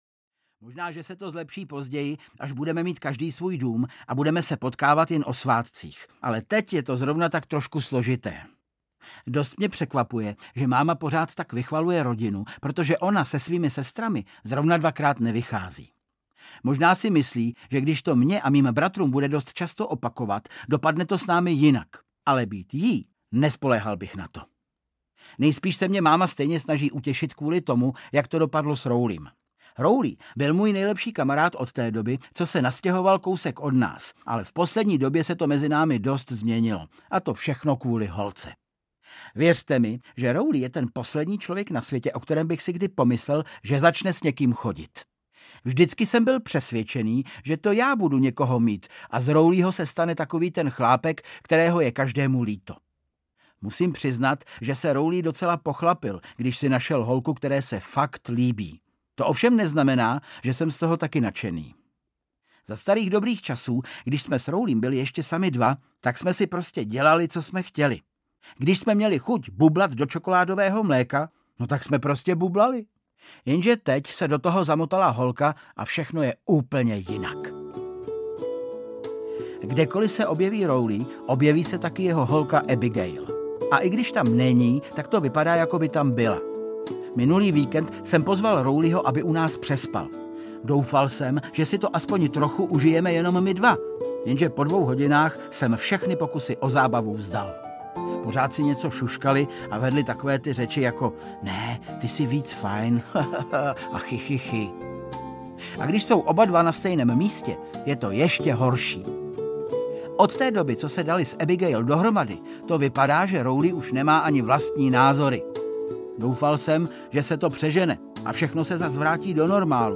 Deník malého poseroutky 8 – Fakt smůla audiokniha
Ukázka z knihy
• InterpretVáclav Kopta